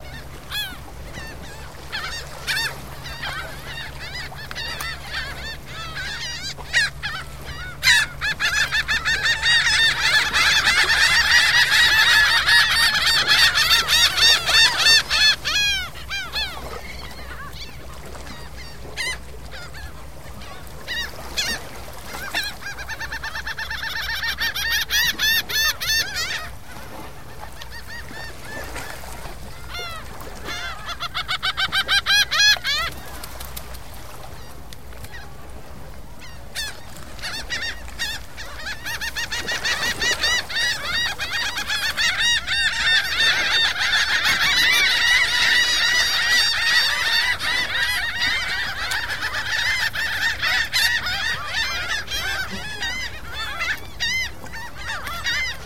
Звуки чайки
Чайки кричат, когда им бросают рыбу у причала